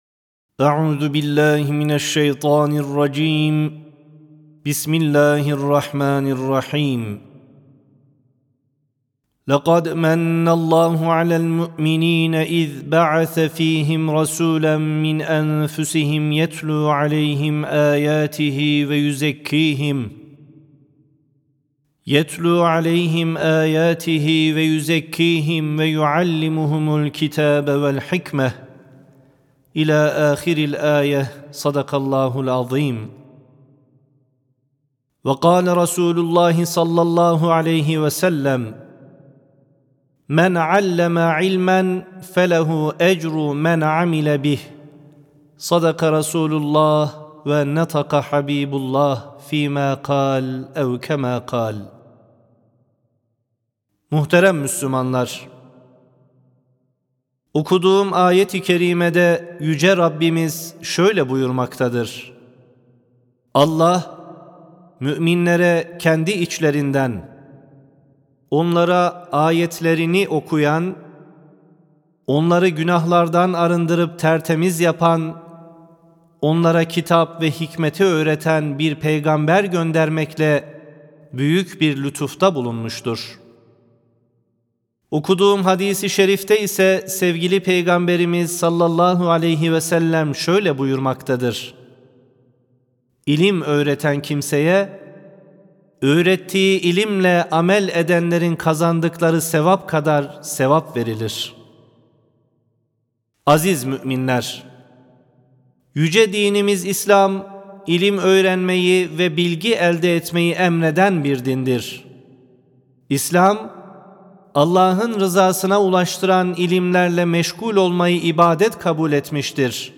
HUTBE
Sesli Hutbe (İlim İzzete, Şiddet Zillete Götürür).mp3